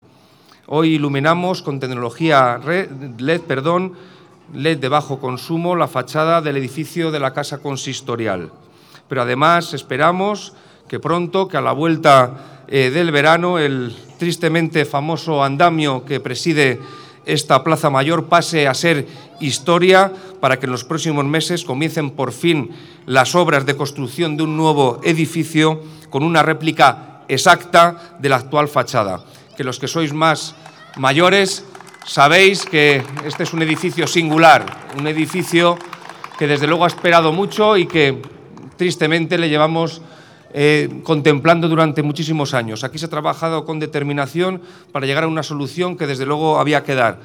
Fue en la noche del miércoles, coincidiendo con el estreno de la nueva iluminación LED del Ayuntamiento de Guadalajara, cuando el alcalde aventuraba en público una fecha, no demasiado inminente, para el derribo de la fachada del «Maragato», bien conocido por su ostentoso andamio.